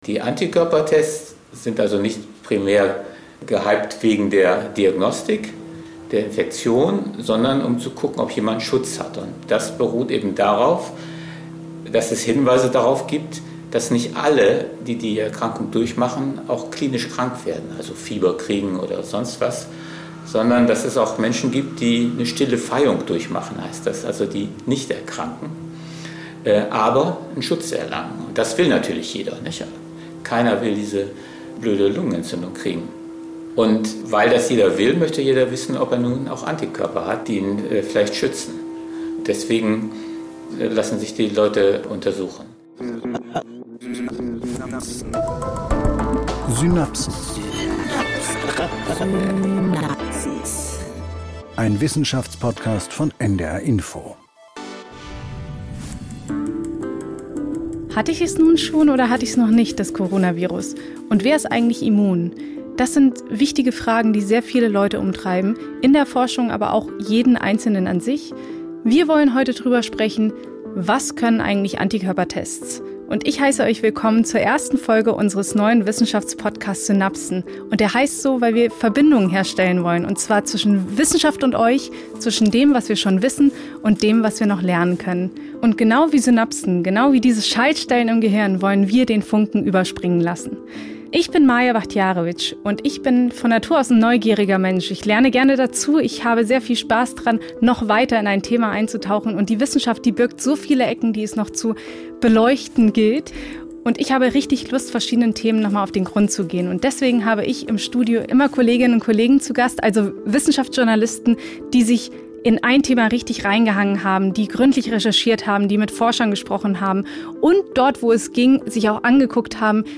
Höre NDR Info live auf unserer Seite, das vom Norddeutschen Rundfunk produzierte wortorientierte Informations- und Kulturprogramm, das in den deutschen Bundesländern Hamburg, Schleswig-Holstein, Mecklenburg-Vorpommern, Niedersachsen und Bremen ausgestrahlt wird!
Alle Viertelstunde werden Nachrichten übertragen und sie werden regelmäßig mit Wetter- und Verkehrsinformationen ergänzt.